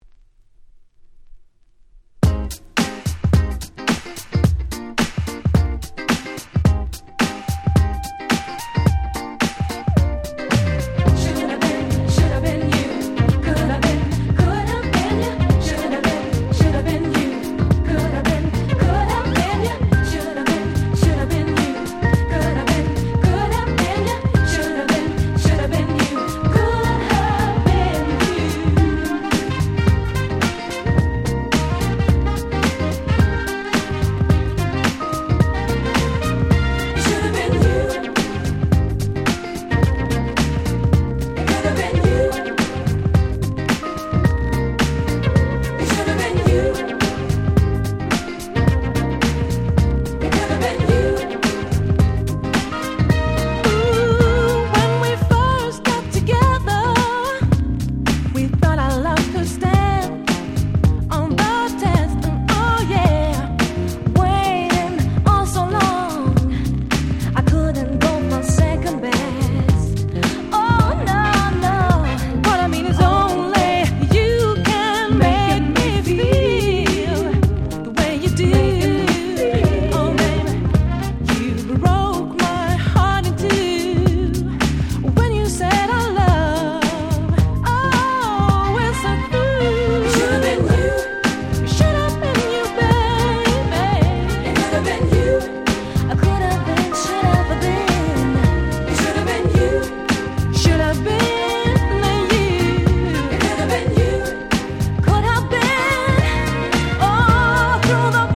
またこのRemixが強烈で、跳ねたADMビートに軽快な歌とメロディーが絡む恐ろしい程に出来の良いRemixなのです！！